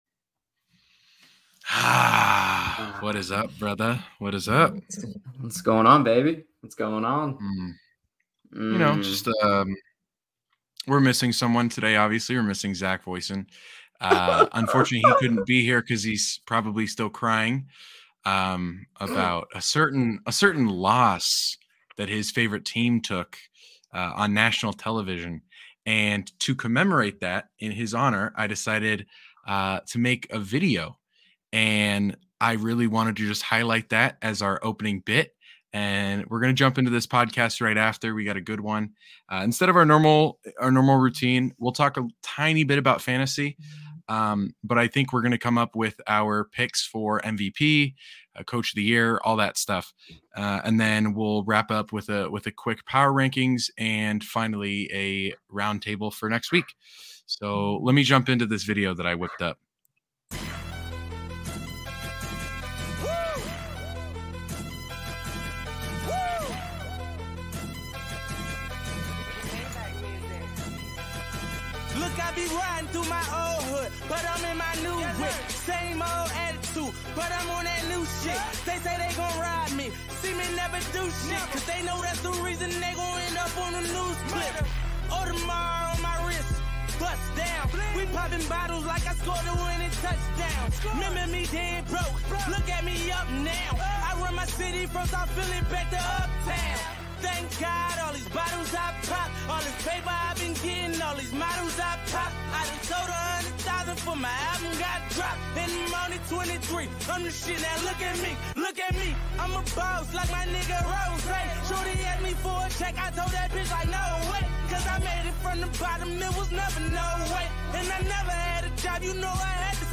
There is a lot of fun banter and humorous commentary which makes it entertaining for football fans.